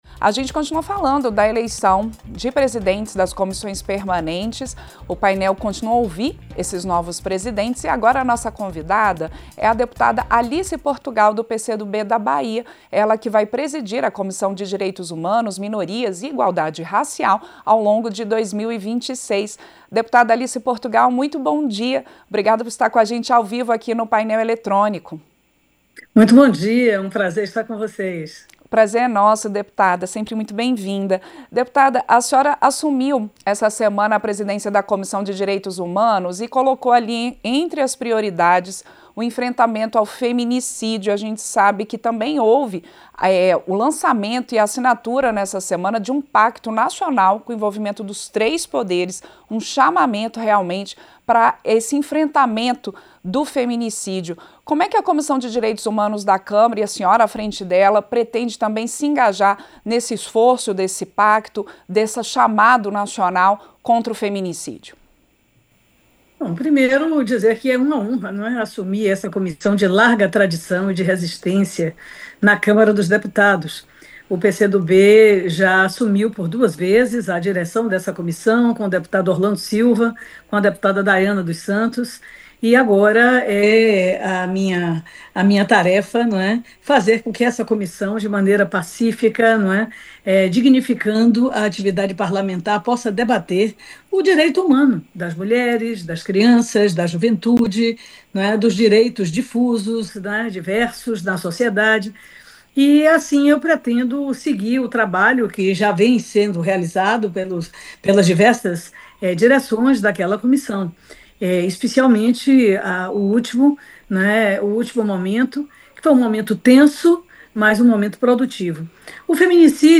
Entrevista - Dep. Alice Portugal (PCdoB/ BA)